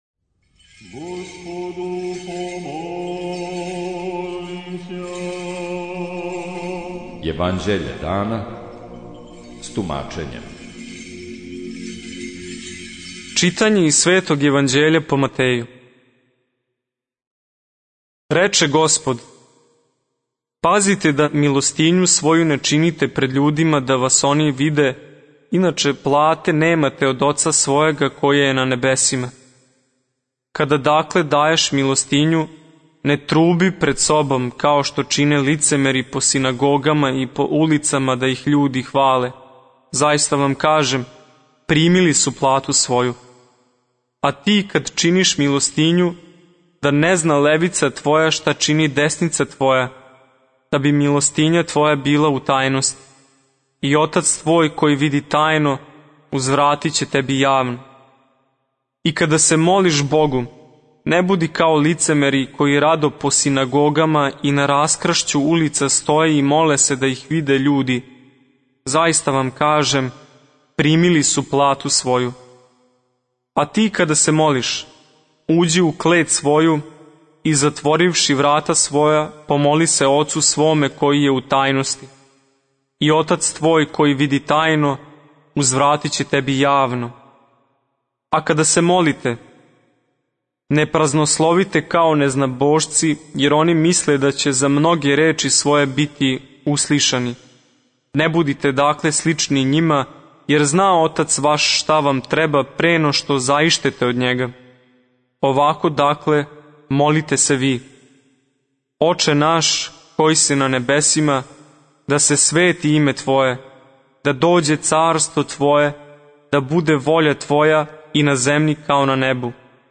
Читање Светог Јеванђеља по Луки за дан 08.10.2023. Зачало 17.